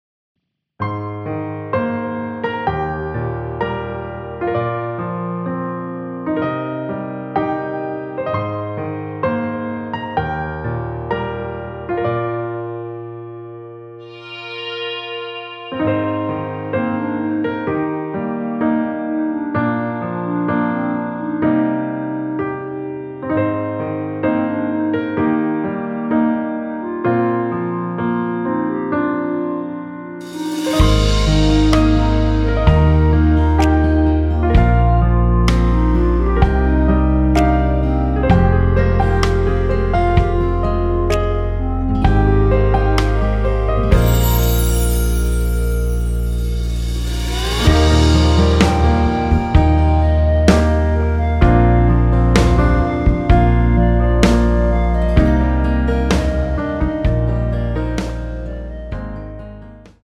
Eb
멜로디 MR이라고 합니다.
앞부분30초, 뒷부분30초씩 편집해서 올려 드리고 있습니다.